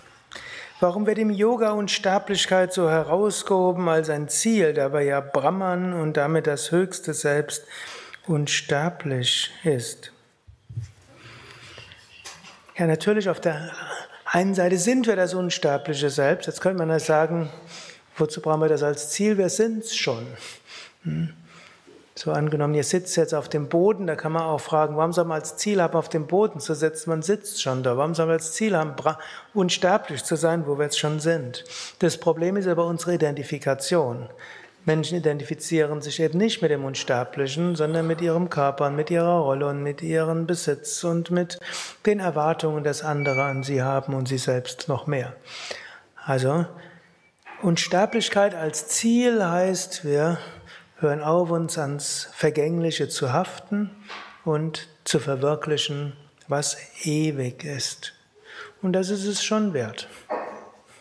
Kurzvorträge
kurzer Vortrag als Inspiration für den heutigen Tag von und mit
Satsangs gehalten nach einer Meditation im Yoga Vidya Ashram Bad